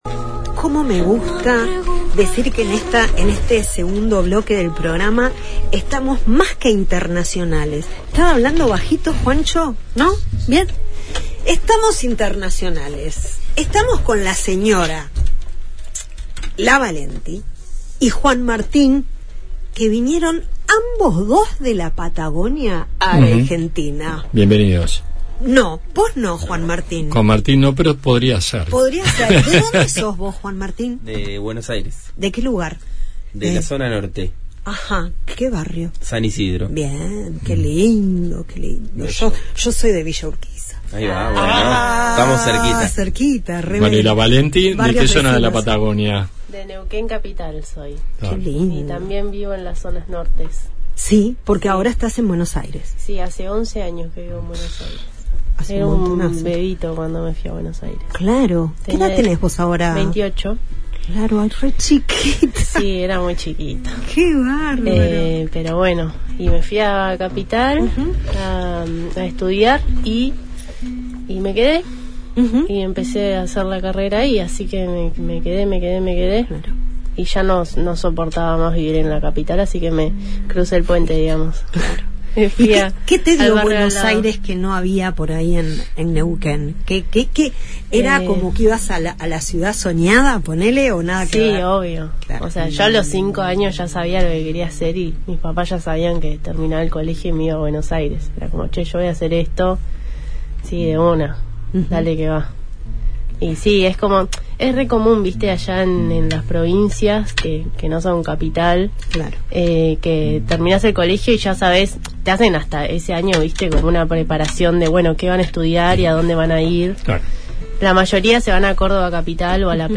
Desde La Patagonia a nuestros estudios